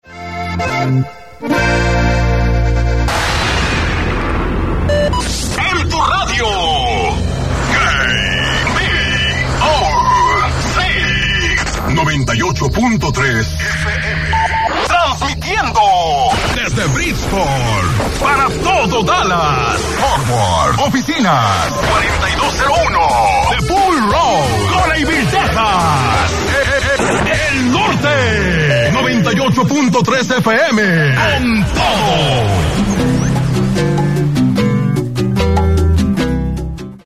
KBOC Top of the Hour Audio: